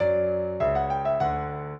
piano
minuet1-4.wav